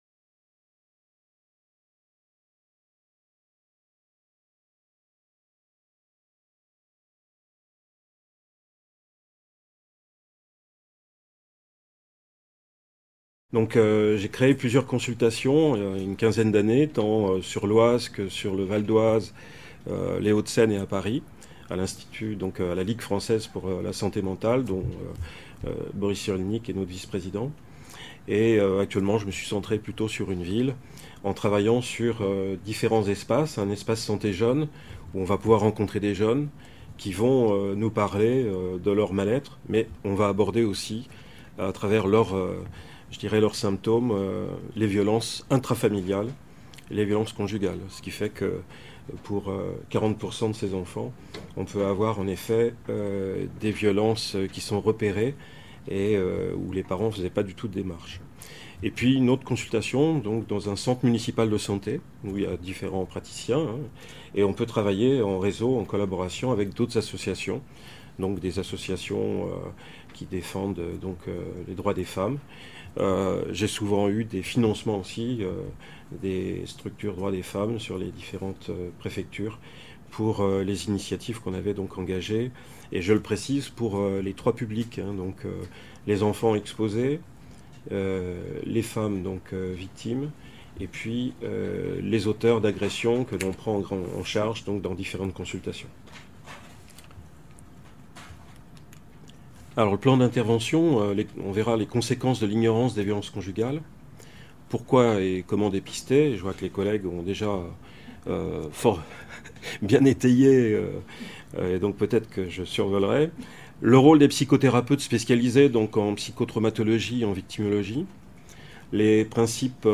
Colloque des Femmes Prévoyantes Socialistes (FPS) : " Violences relationnelles et santé : quels liens, quels soins ? - 9 Mai 2014 - Bruxelles